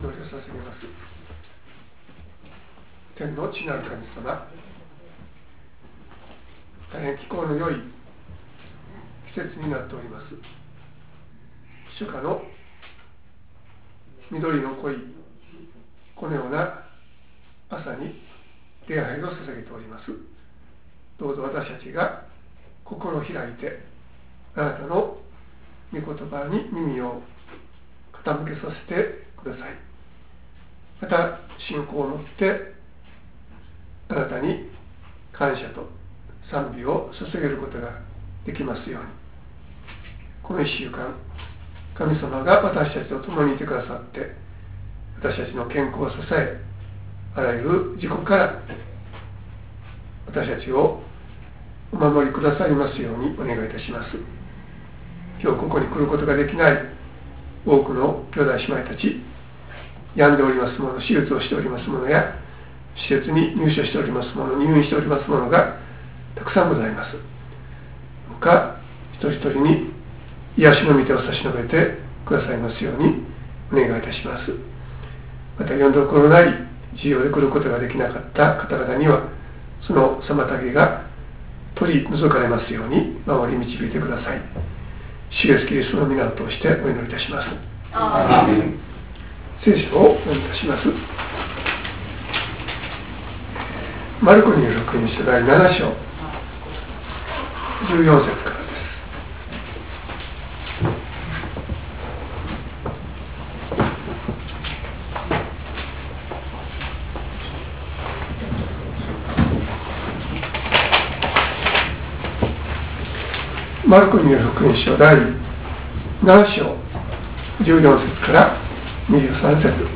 説教「４０００人給食の奇跡」